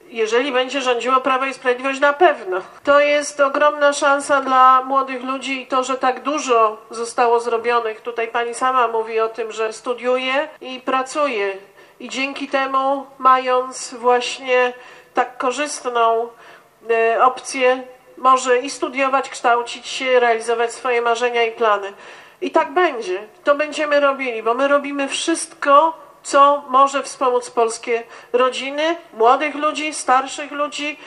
Kontynuację zwolnienia z podatku dla osób pracujących przed 26 rokiem życia zadeklarowała europoseł, była premier Beata Szydło podczas spotkania w Sandomierzu z mieszkańcami regionu.